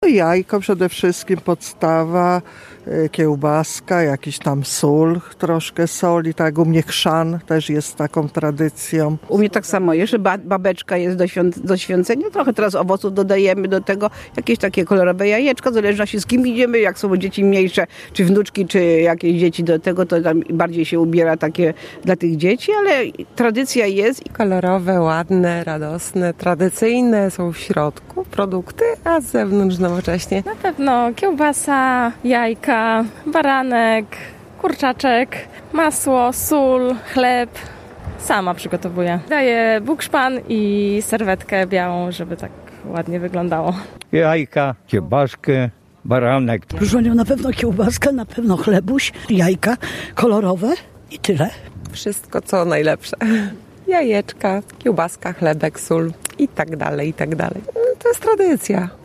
Wielkanocny koszyk, sonda